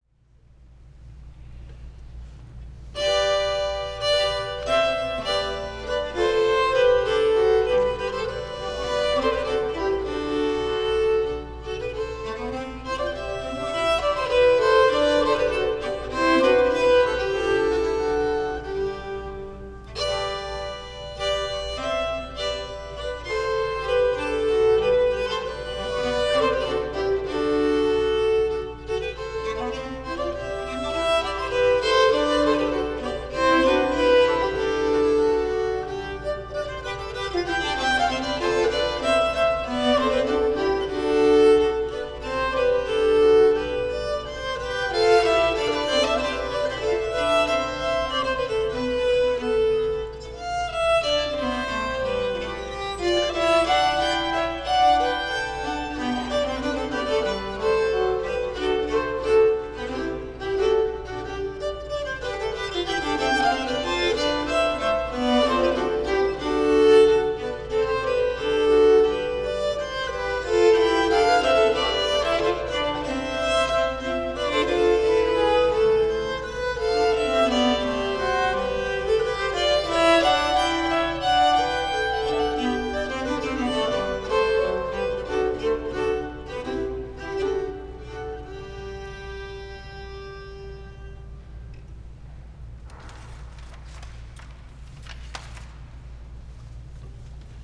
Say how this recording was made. > I ran your original unedited firsth through noise reduction and then fft emphasizes the rumble, and they are hearing the original?).